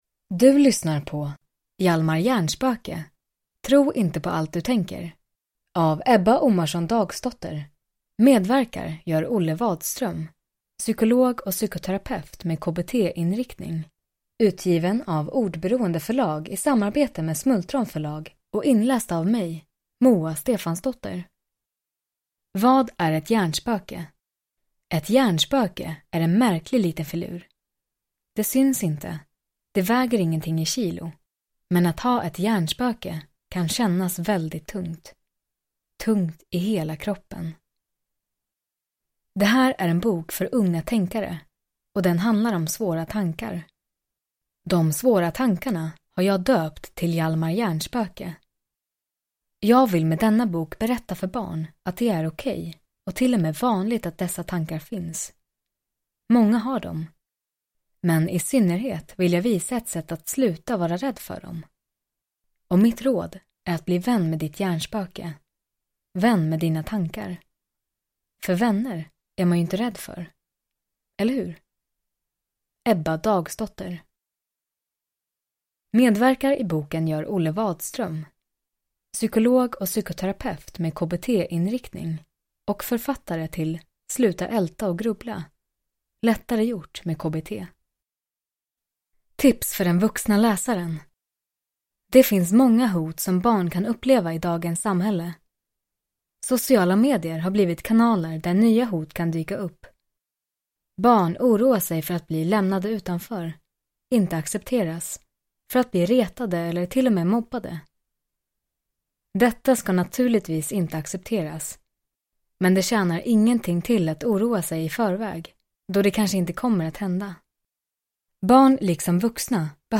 Hjalmar Hjärnspöke – Ljudbok – Laddas ner